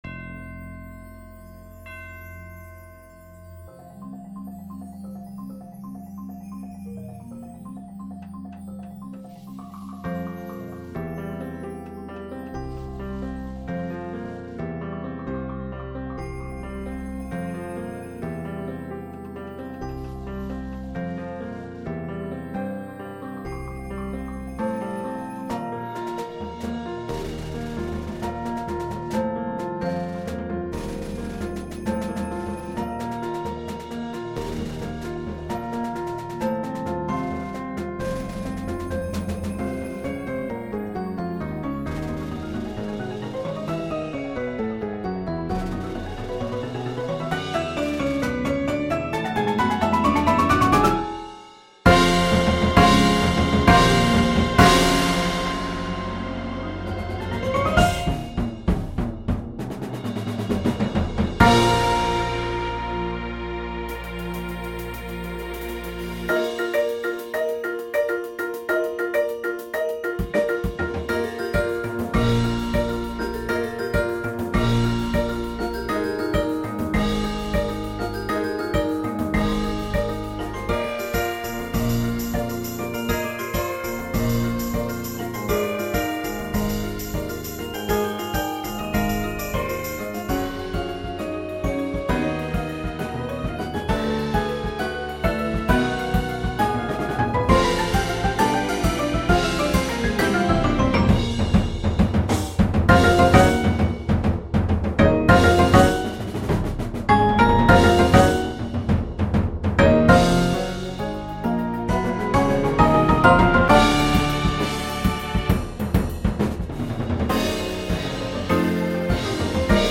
is an exciting work as well as being reflective and serene
• Bells
• Chimes
• 2 Xylophones
• 4 Marimbas Guitar
• 2 Synthesizers Bass Guitar
• Drum Set
• 4 Aux. Percussion